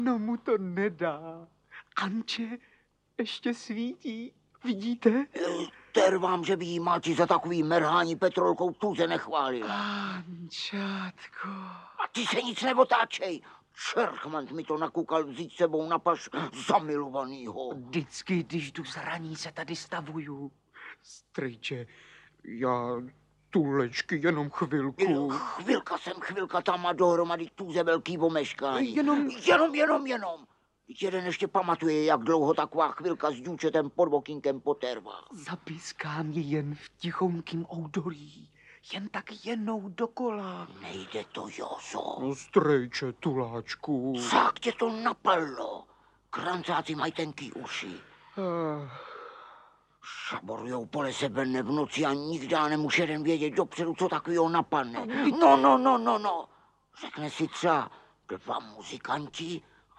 Audiobook
Read: František Filipovský